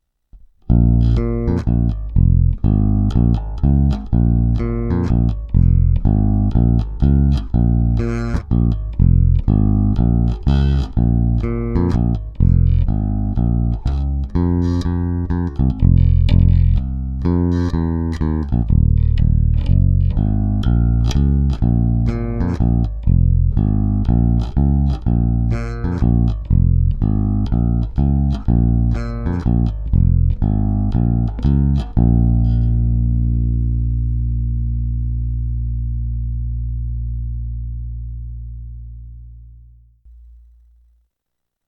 2) z DI výstupu zesilovače